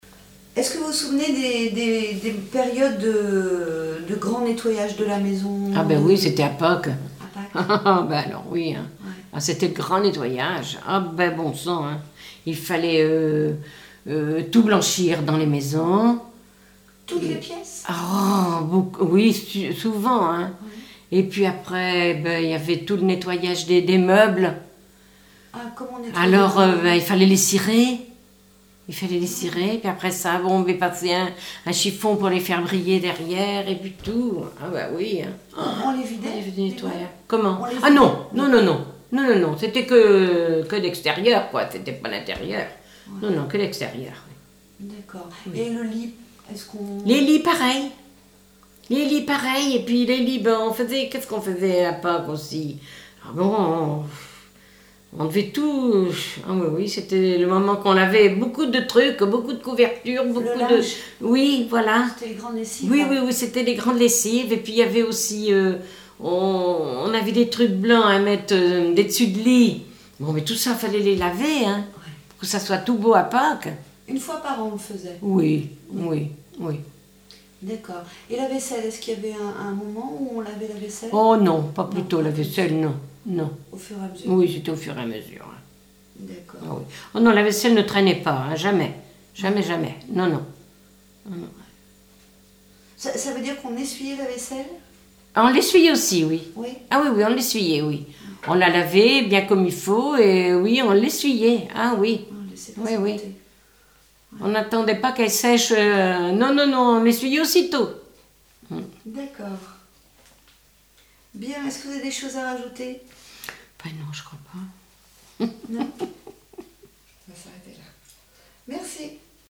Témoignages sur la vie à la ferme
Catégorie Témoignage